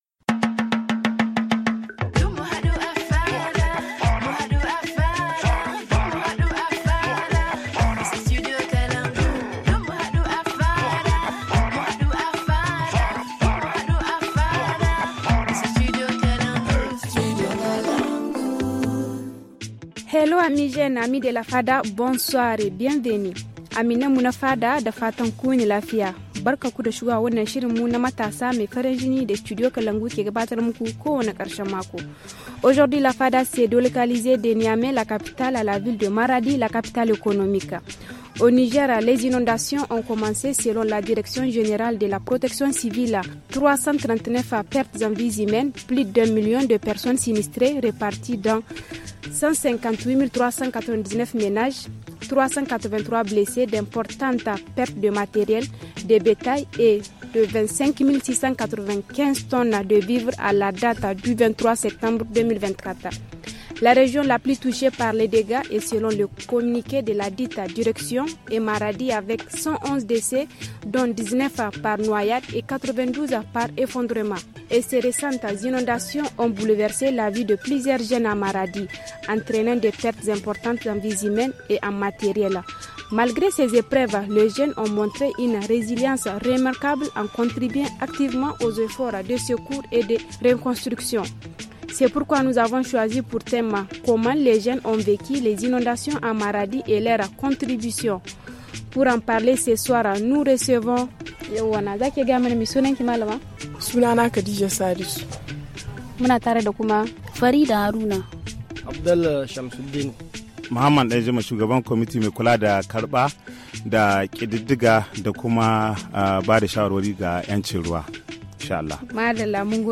Ce soir, la Fada s’est réunie à l’école Diori pour discuter de l’impact des inondations sur les activités des jeunes de Maradi.